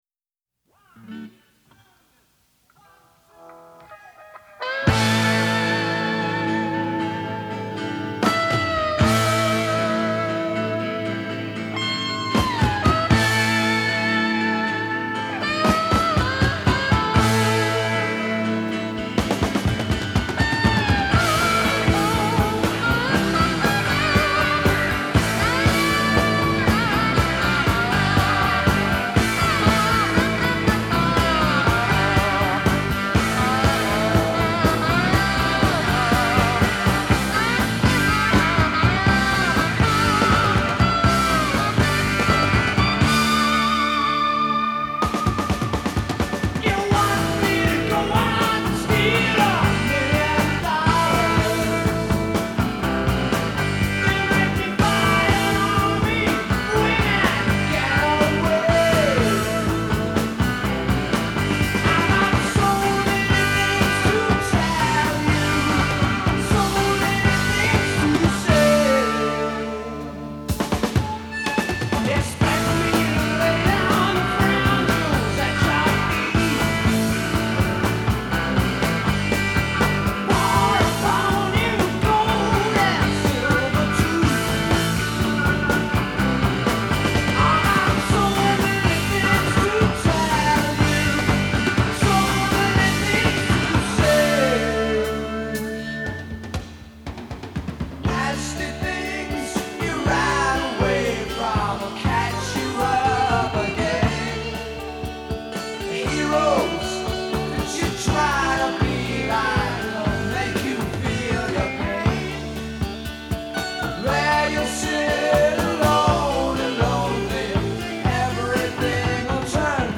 Country: Rock, Hard Rock, Blues Rock